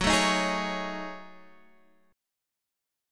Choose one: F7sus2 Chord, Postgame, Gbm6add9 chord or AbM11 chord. Gbm6add9 chord